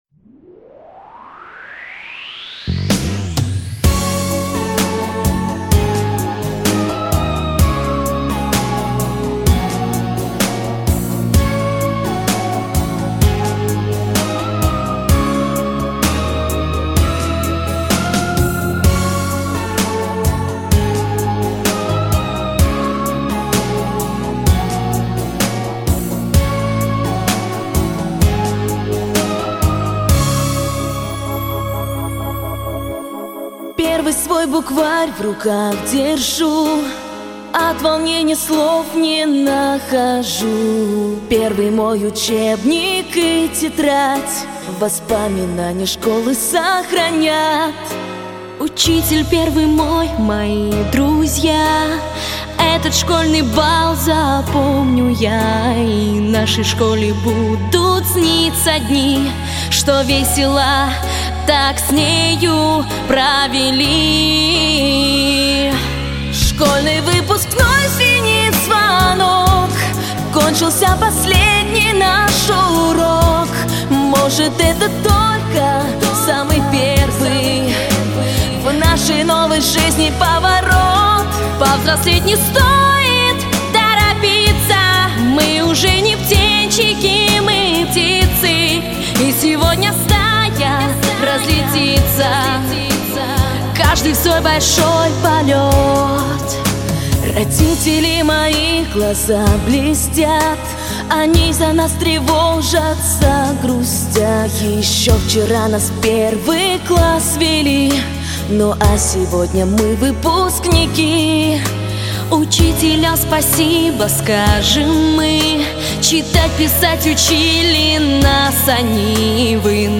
Главная / Песни для детей / Песни про школу